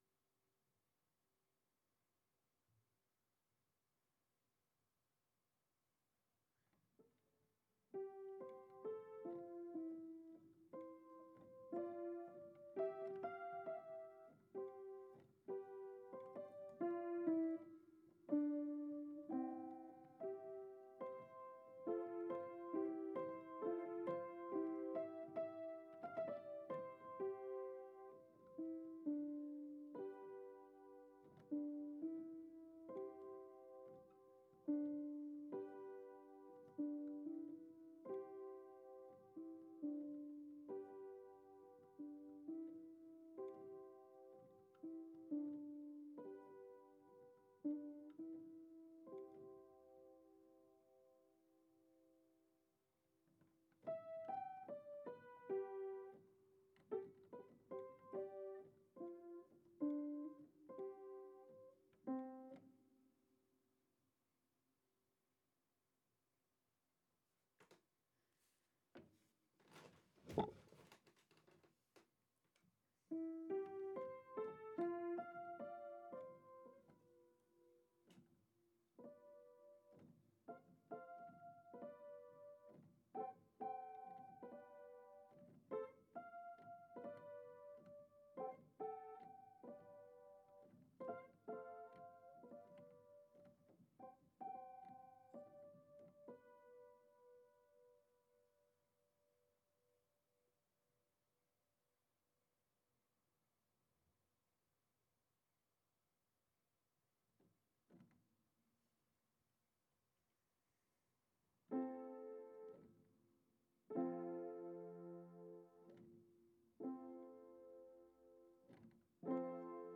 Piano
Here is the unprocessed piano improvising which was used as the basis for the other files used in the film soundtrack.